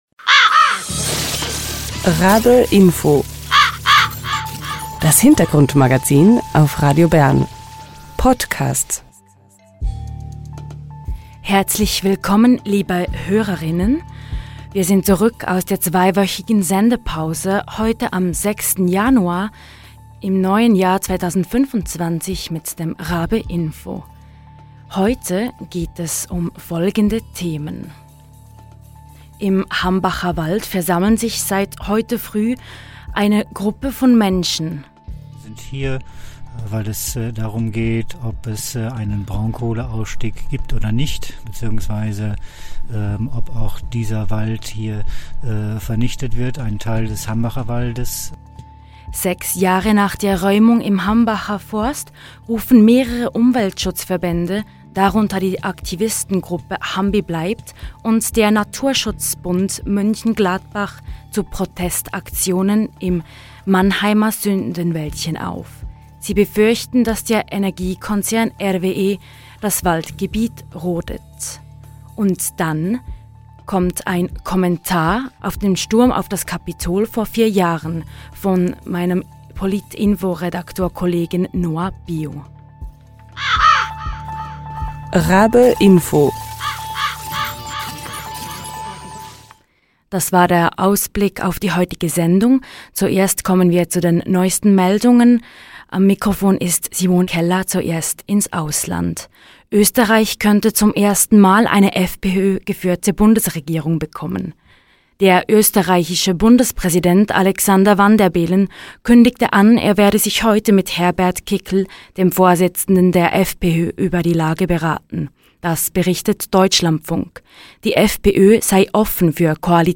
Aktivist*innen protestieren im Hambacher Wald gegen drohende Waldrodungen. Ein Interview vom Hamburger Radio FSK mit einem Aktivisten vor Ort.